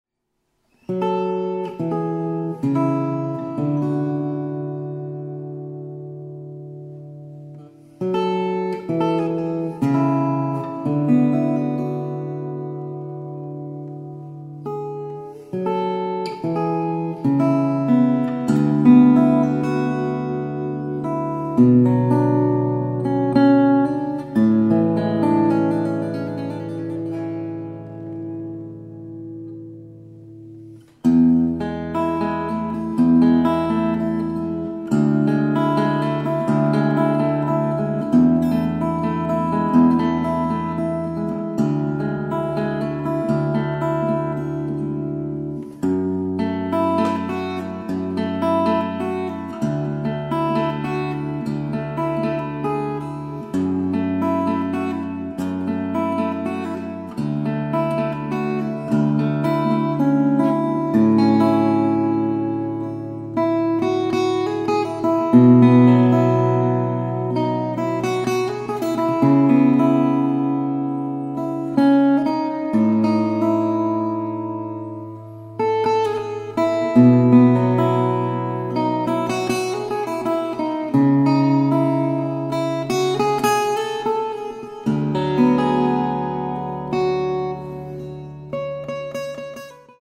Composition & acoustic guitar